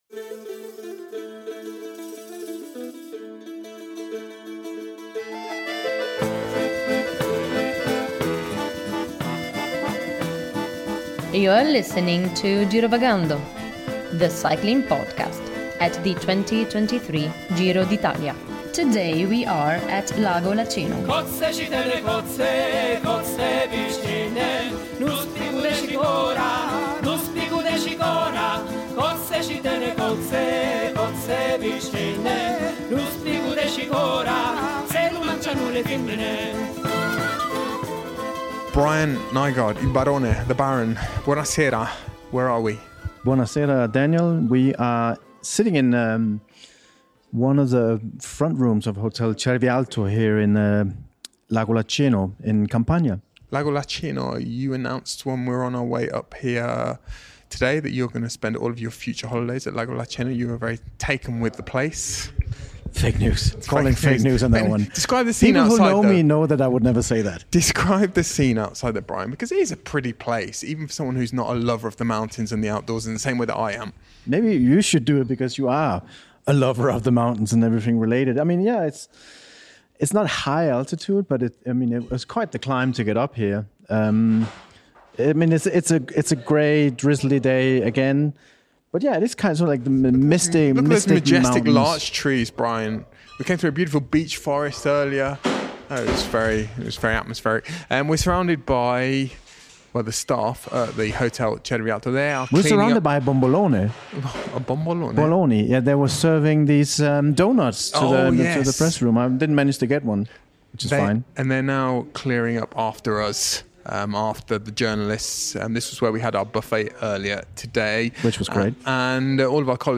In this episode of Girovagando, we hear news, interviews and opinion from stage 4 of the Giro d’Italia